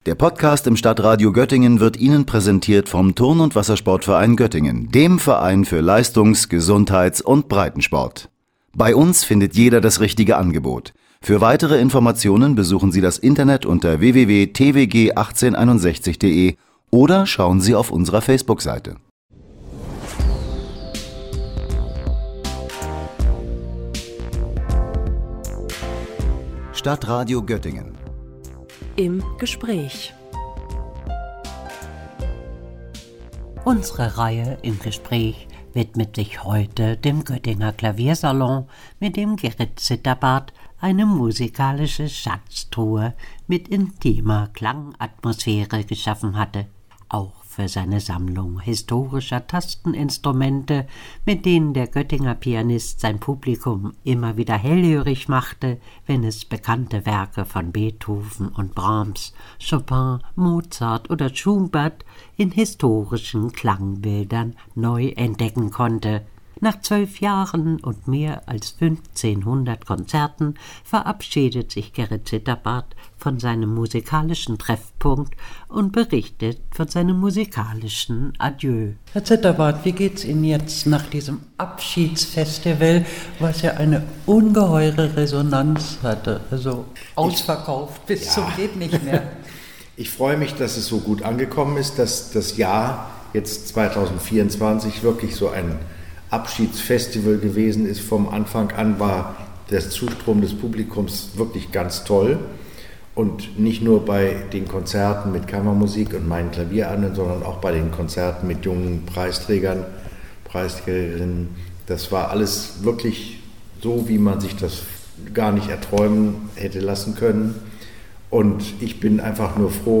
Ein musikalisches Adieu für den Göttinger Clavier-Salon – Gespräch